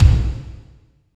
36.07 KICK.wav